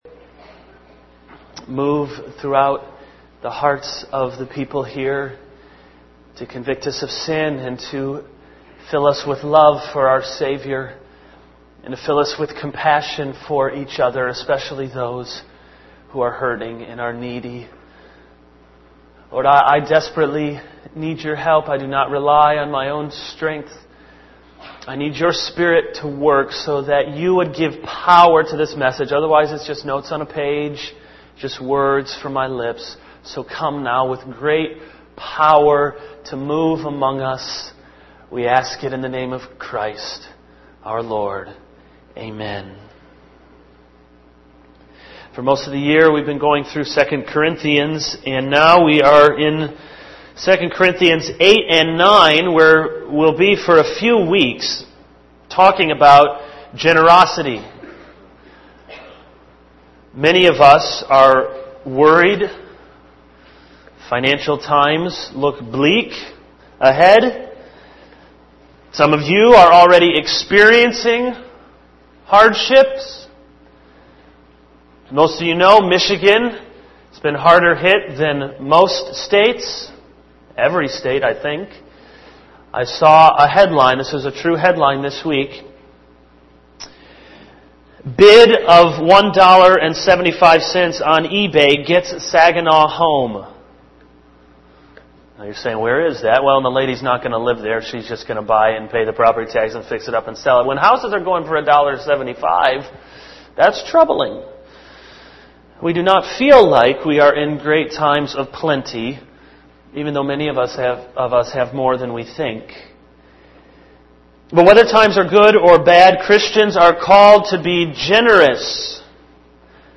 This is a sermon on 2 Corinthians 8:8-15.